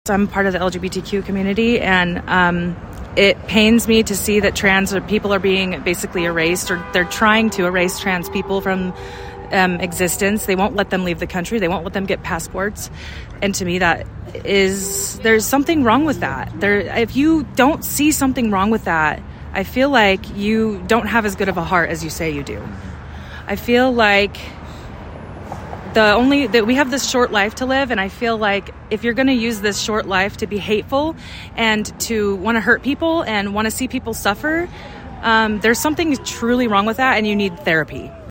Two groups stood on each side of the street as they chanted and held signs. Cars passing by either honked, cheered or opposed the crowd.